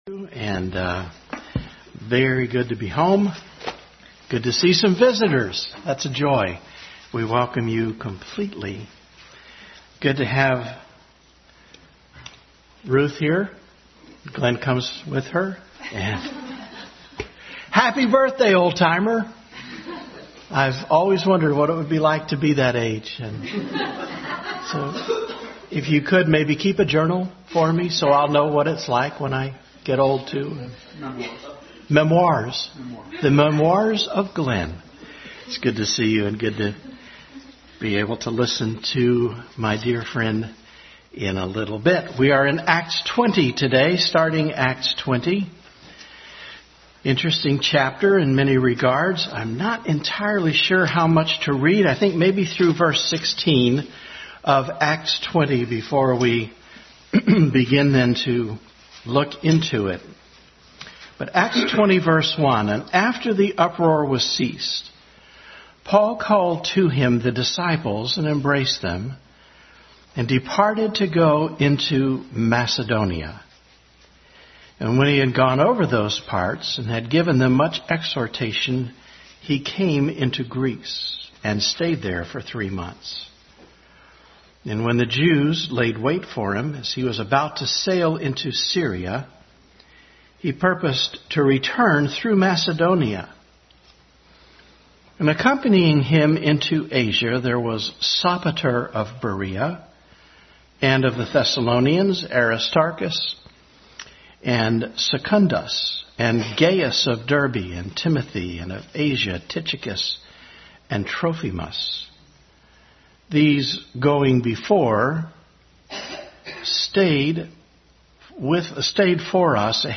Bible Text: Acts 20:1-16 | Continued study in the book of Acts during Sunday School hour.
Acts 20:1-16 Service Type: Sunday School Bible Text